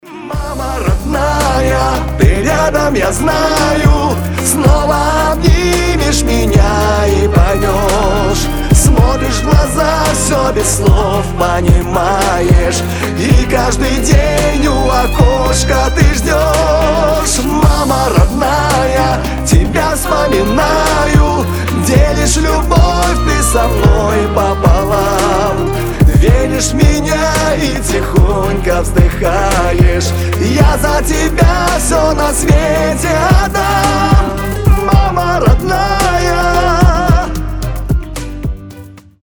• Качество: 320, Stereo
красивые
душевные
скрипка